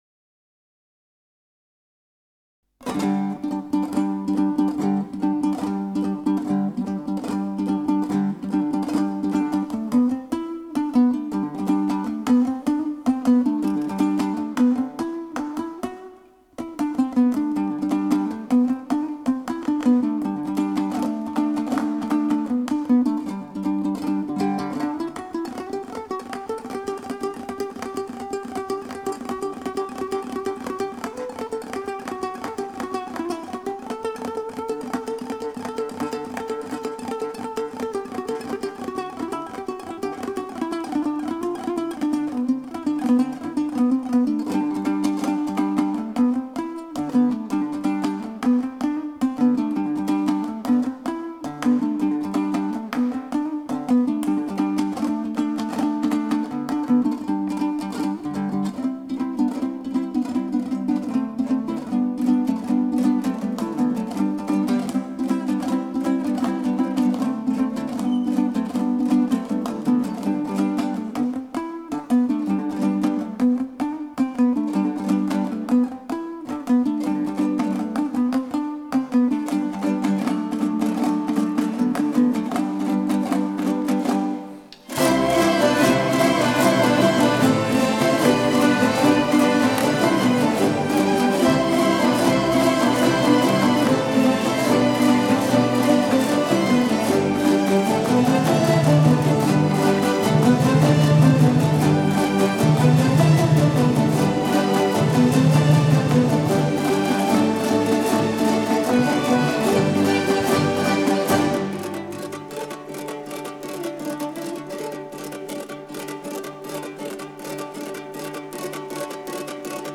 таких как домбра.